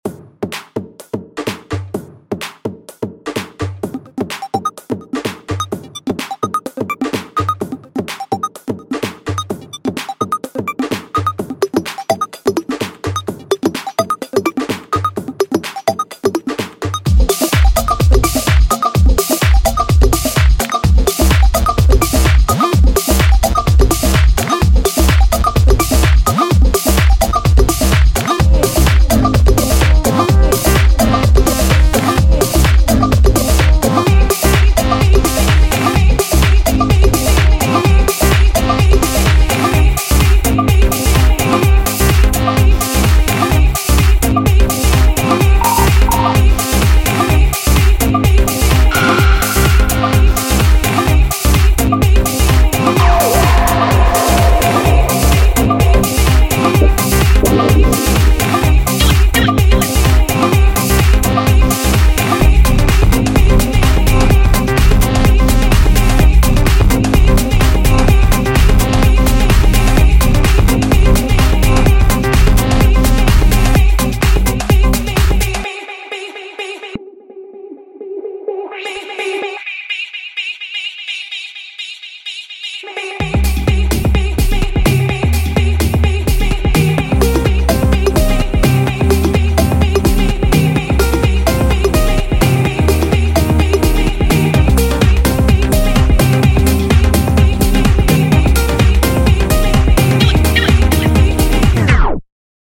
BPM127
Audio QualityPerfect (High Quality)
Can you feel your heart racing to this groovy beat?